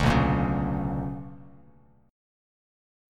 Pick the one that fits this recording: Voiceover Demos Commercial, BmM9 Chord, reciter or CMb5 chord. BmM9 Chord